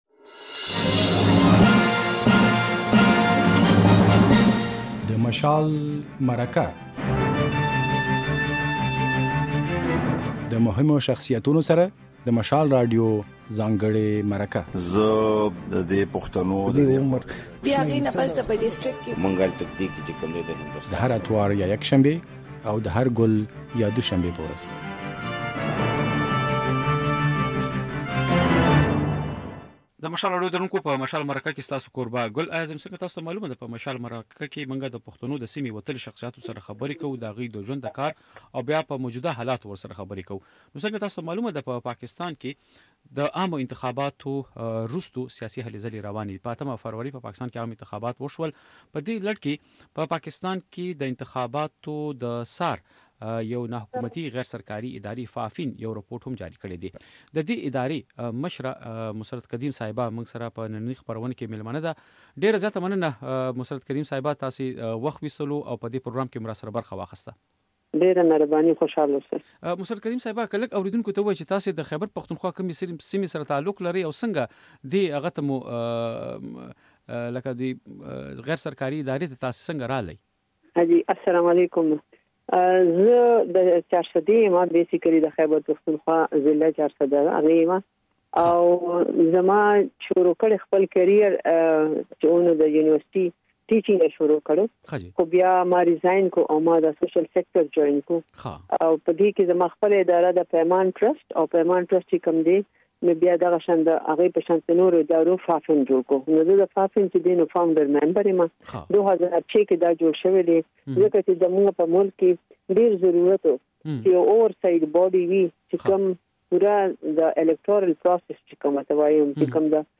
له هغې سره ځانګړې مرکه واورئ.